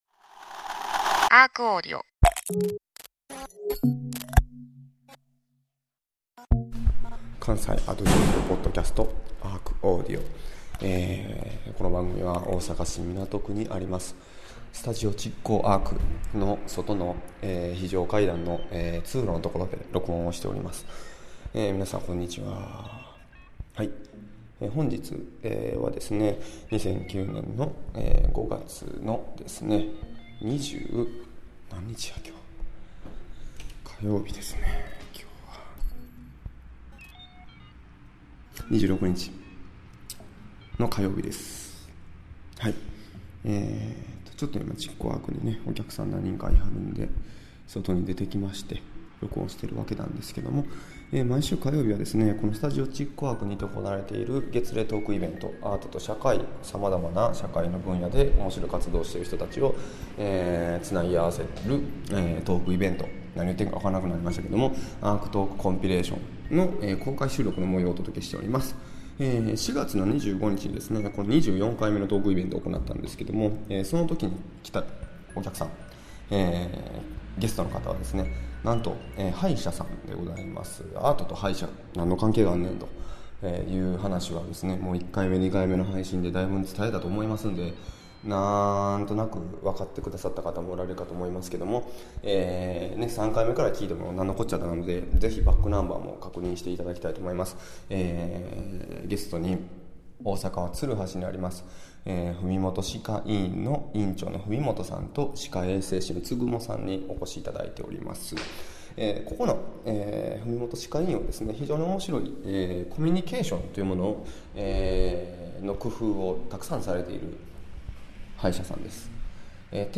5/26(火) ARCAudio!! トーク「予防医療を通して、あなたの暮らしをみつめる」3/3